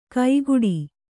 ♪ kaiguḍi